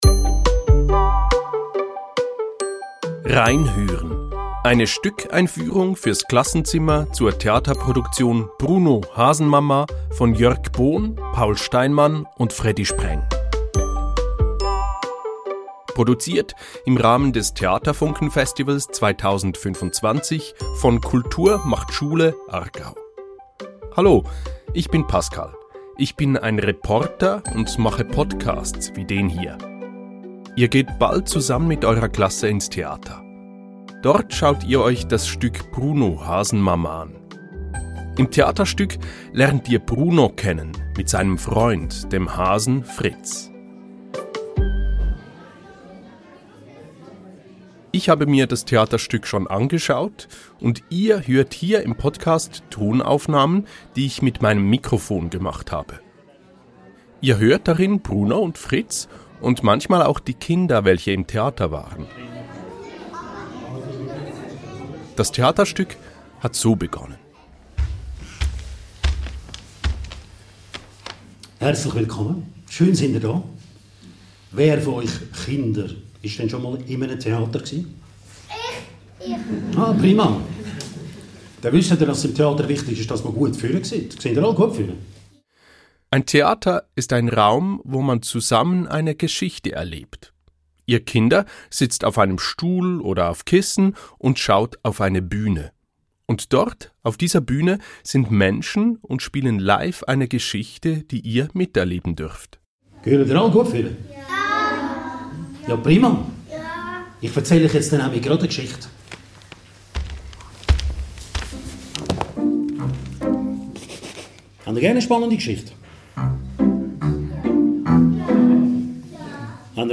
Eine Stückeinführung für das Klassenzimmer.